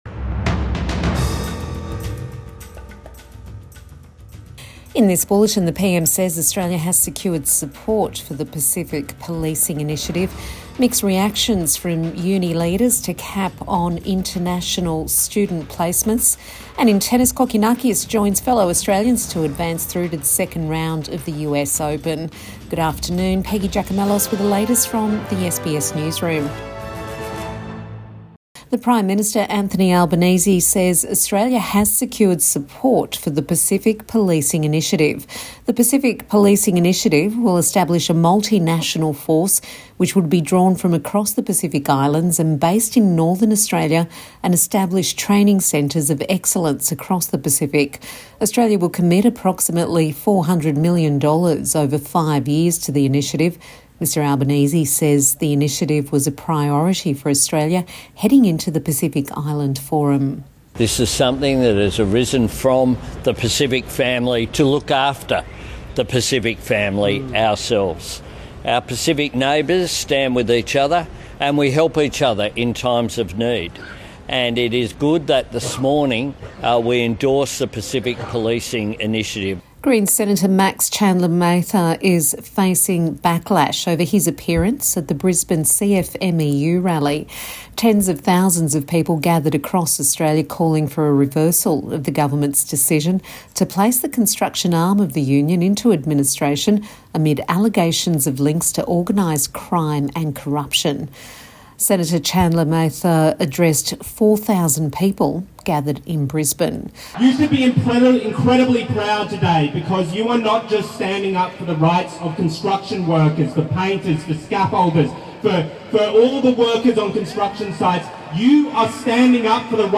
Midday News Bulletin 28 August 2024